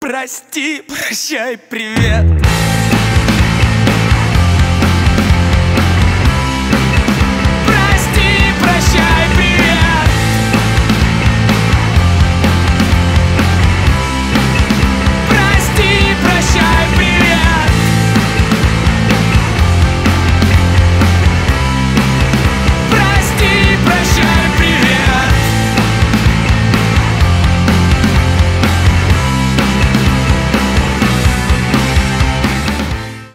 громкие
панк
рок